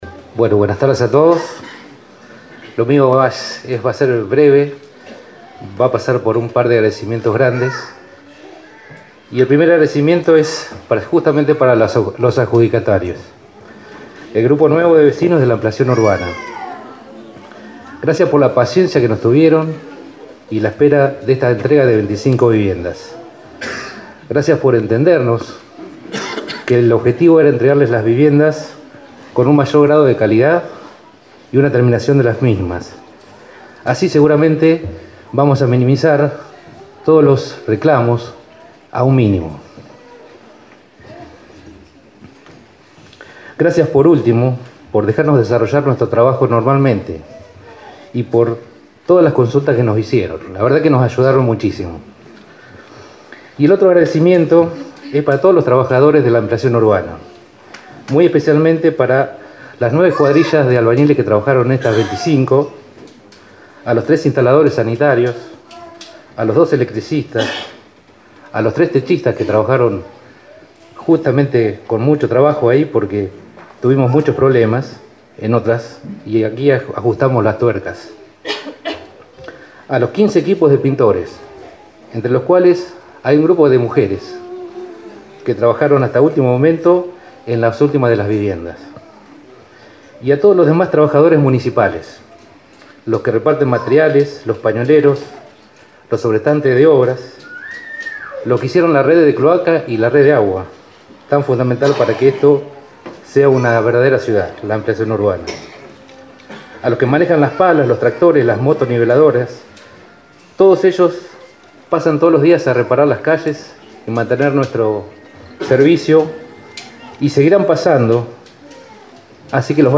El día martes 24 se entregaron 25 viviendas del círculo cerrado en la Ampliación Urbana, el Director de Obras Civiles Gustavo Achaerandio habló ante de los presentes de la siguiente manera.